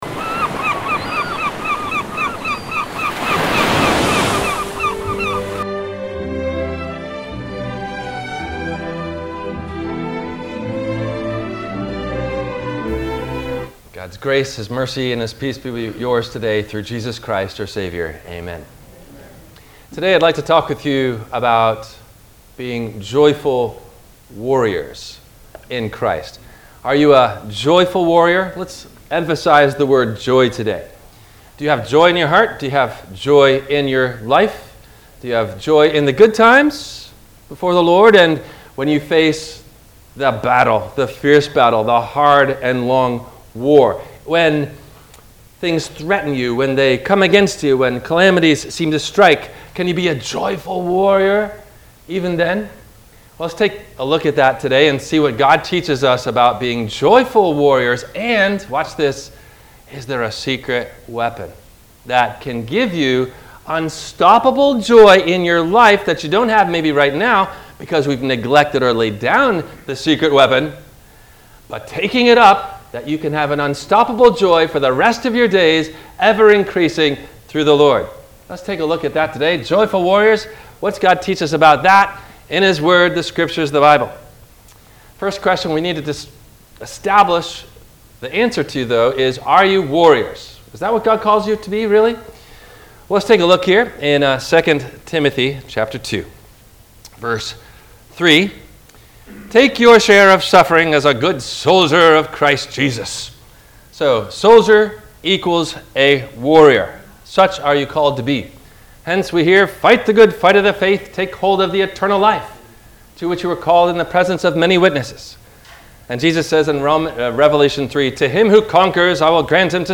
How To Be A Joyful Warrior – WMIE Radio Sermon – December 04 2023